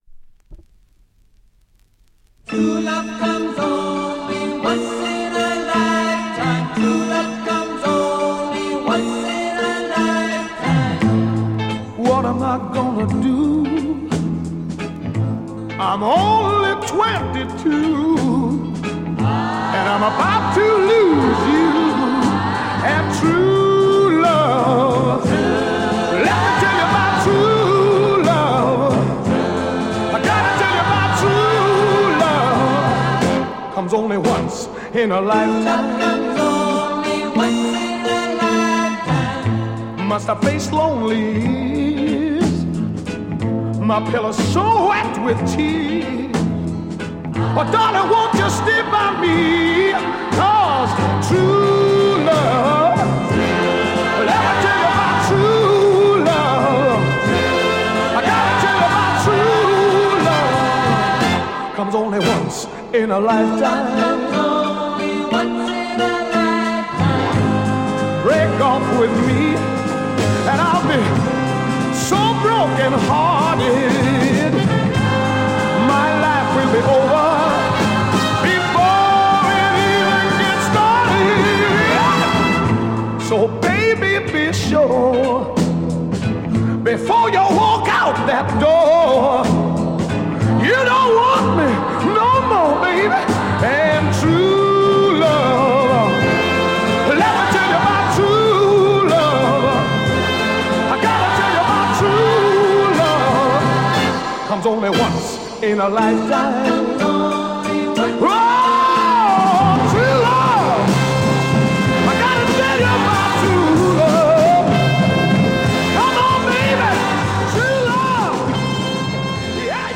Soul Mod
Classic Soul mod French EP nearly perfect condition copy!